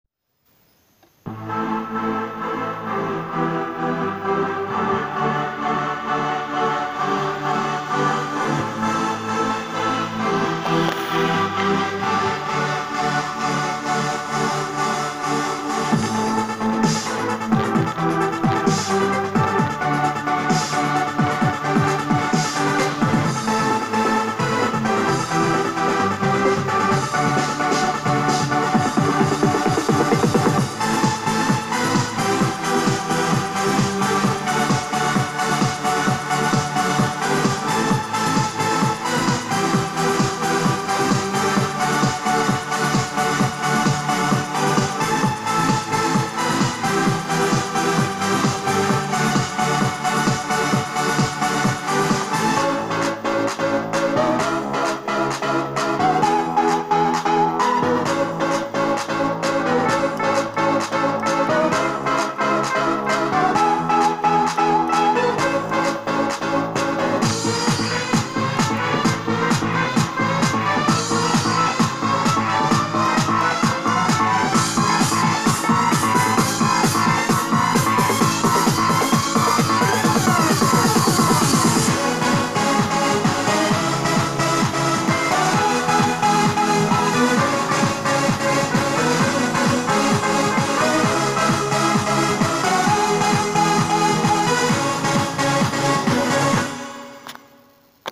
ימאהה 473
תראו מה אפשר להוציא מאורגן של 2000שח חבר שלי מתחיל קנה את האורגן הזה .באורגן הזה אין הקלטה פנימית מהאורגן לכן הוא הקליט את זה אם נגןעט.wav